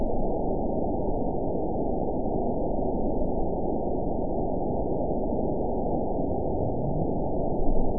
event 919809 date 01/25/24 time 02:58:02 GMT (1 year, 3 months ago) score 9.49 location TSS-AB01 detected by nrw target species NRW annotations +NRW Spectrogram: Frequency (kHz) vs. Time (s) audio not available .wav